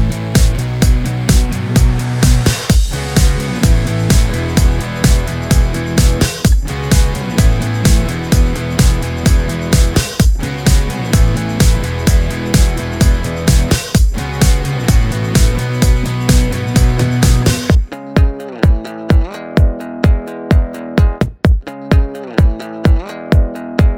no Backing Vocals Pop (2010s) 3:13 Buy £1.50